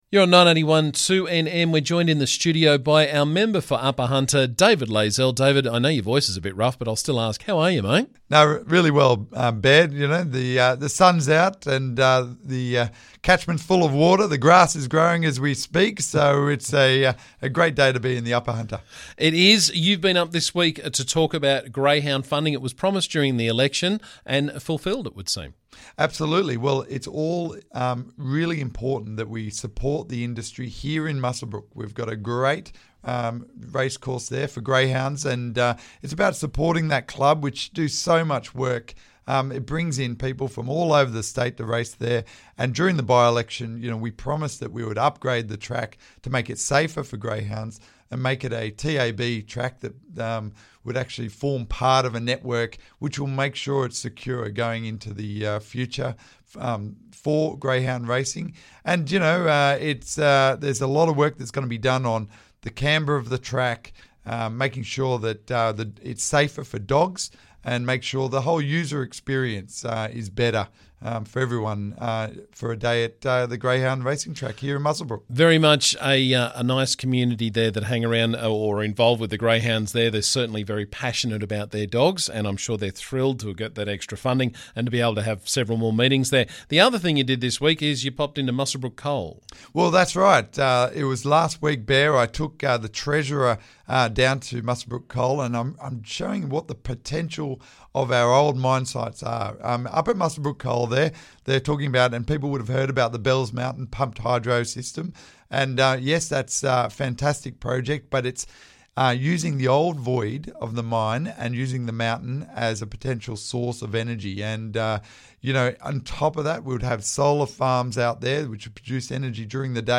Member for Upper Hunter David Layzell stopped in this morning to talk about local greyhound funding and energy alternatives.